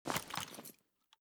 mosin_holster.ogg